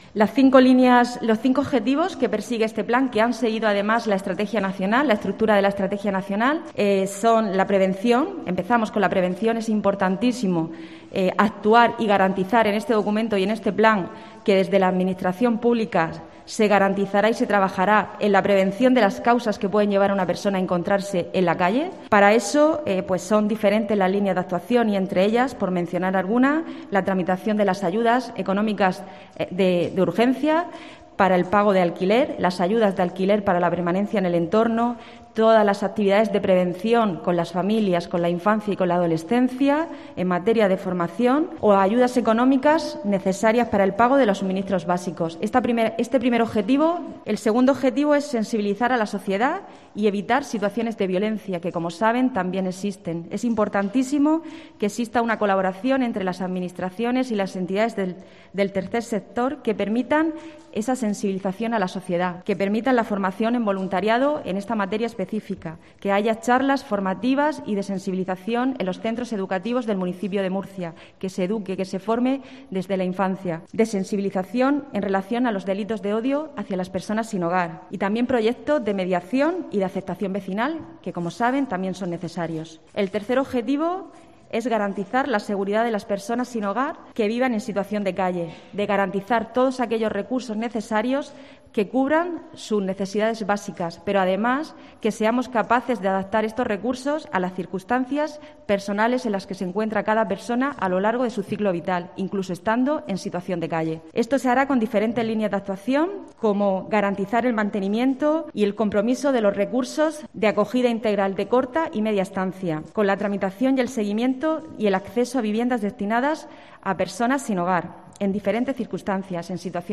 Paqui Pérez, concejala de Mayores, Vivienda y Servicios Sociales